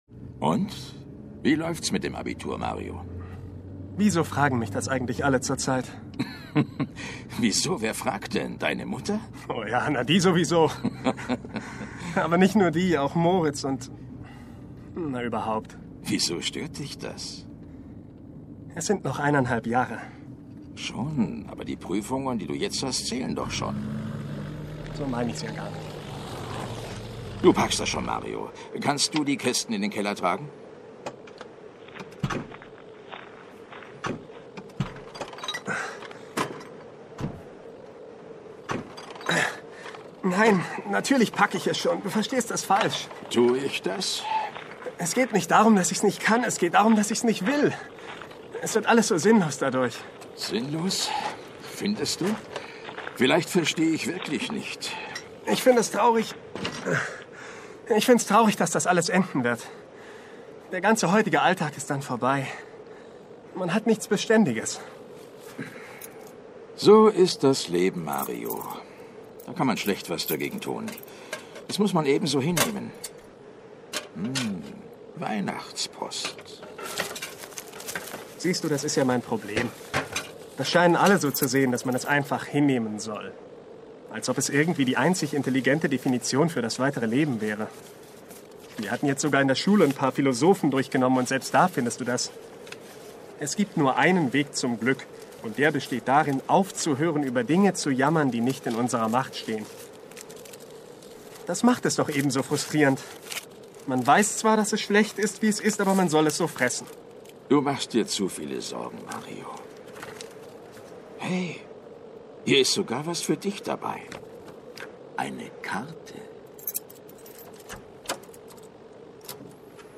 Sprechprobe: Werbung (Muttersprache):
german voice over talent, computer-gemes, audiobooks ...